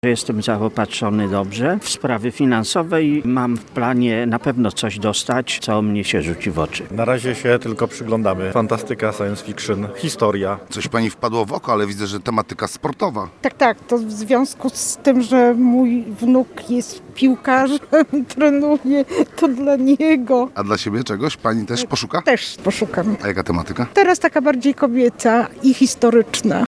Ci, którzy pojawili się na otwarciu wydarzenia nie ukrywali, że liczą na znalezienie książkowych „perełek”. Posłuchaj odwiedzających targi: